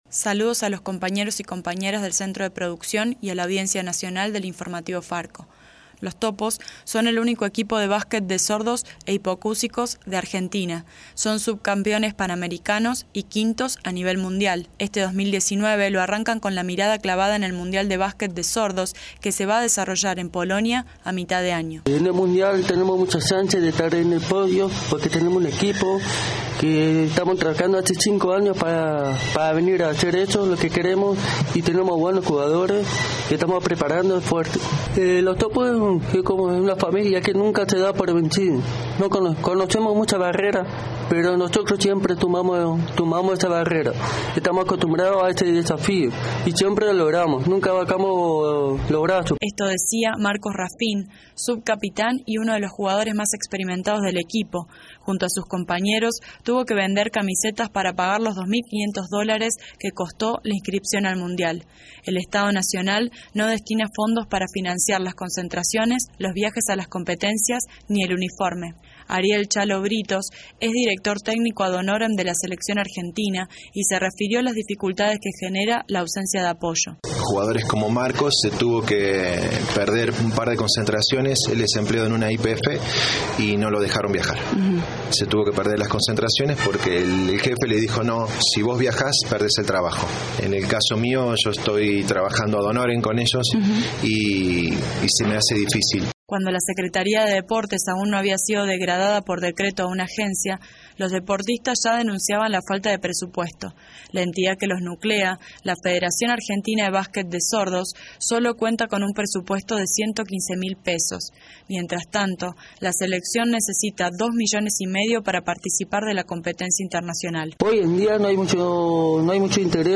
reporte-villanos-07-02.mp3